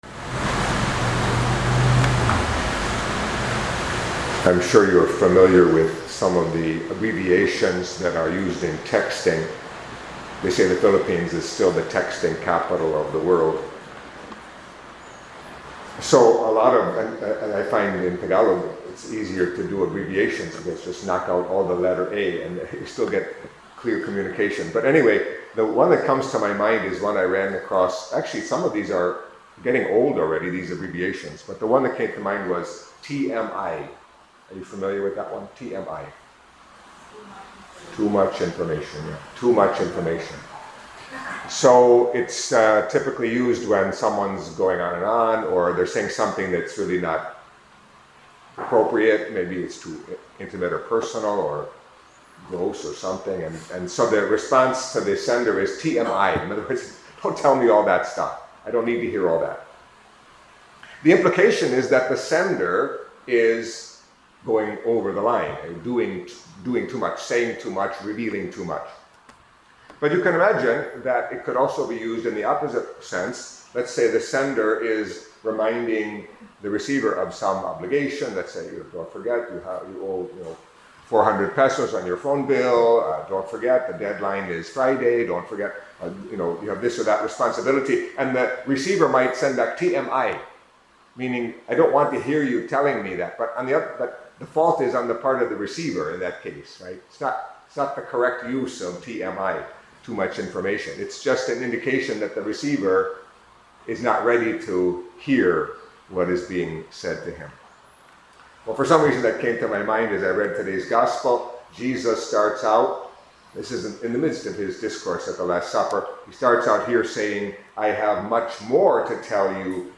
Catholic Mass homily for Wednesday of the Sixth Week of Easter